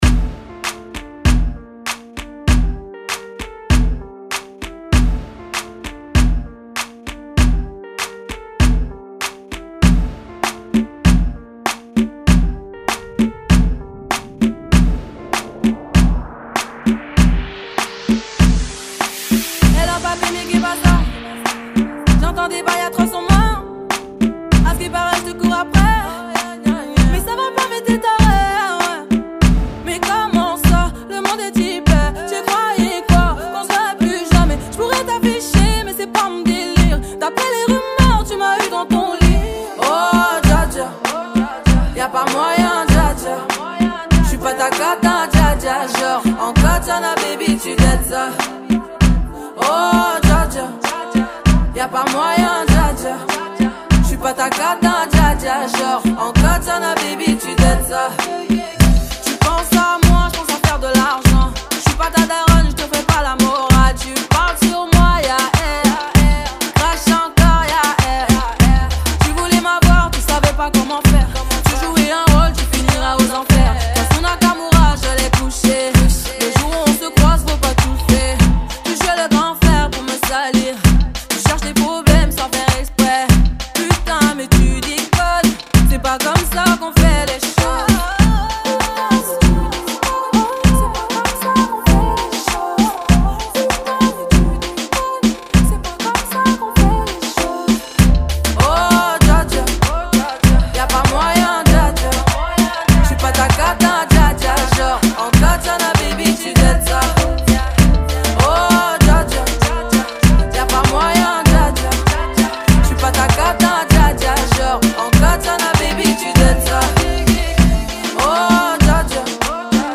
[ 98 Bpm ]